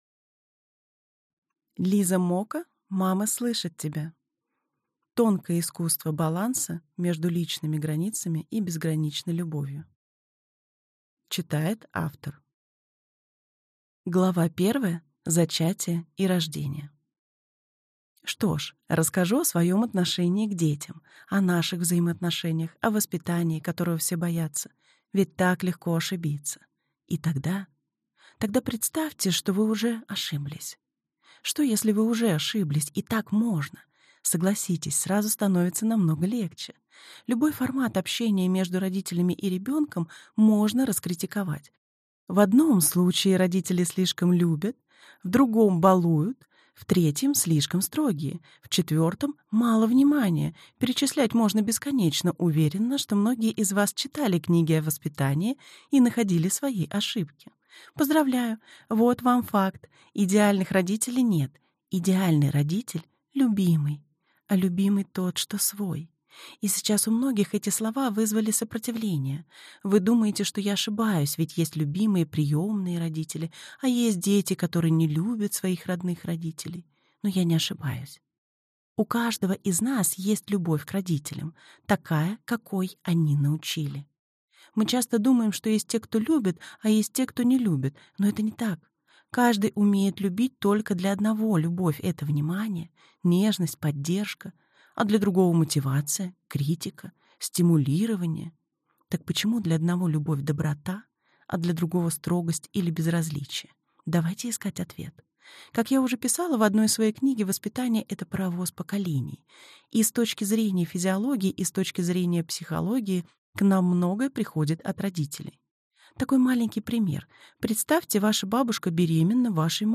Аудиокнига Мама слышит тебя. Тонкое искусство баланса между личными границами и безграничной любовью | Библиотека аудиокниг